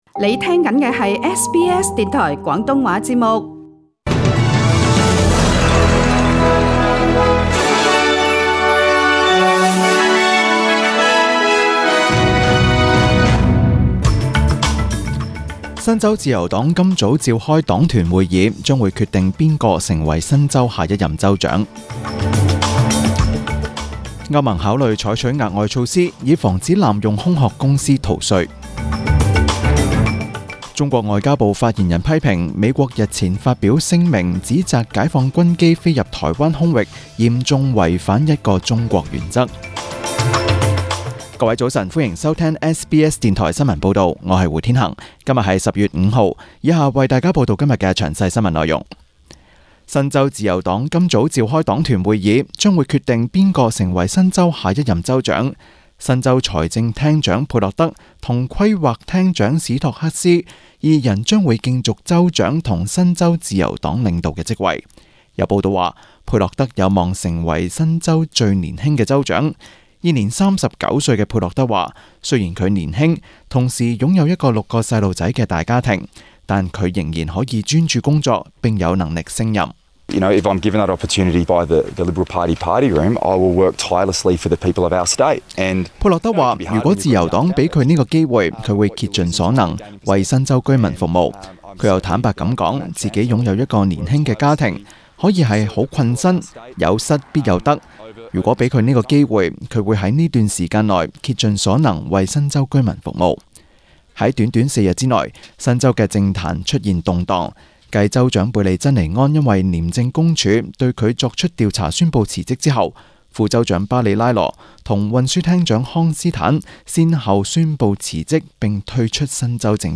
SBS 中文新聞 （十月五日）
SBS 廣東話節目中文新聞 Source: SBS Cantonese